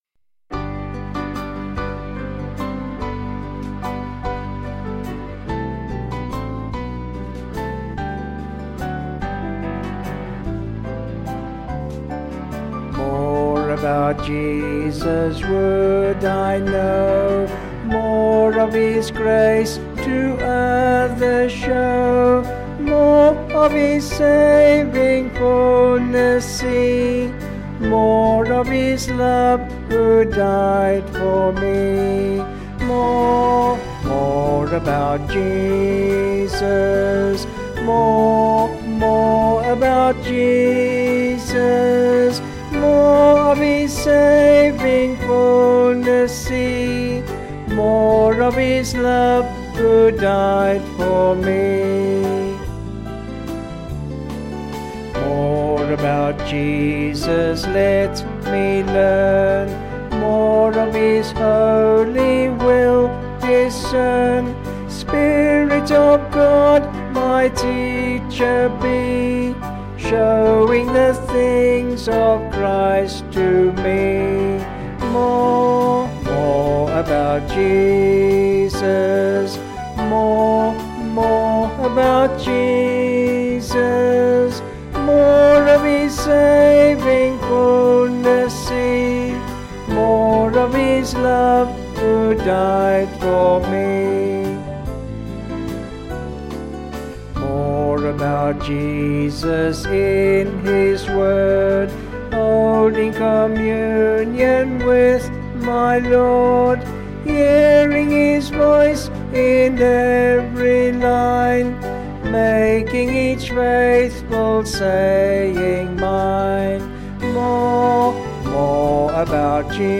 4/G-Ab
Vocals and Band   265.6kb Sung Lyrics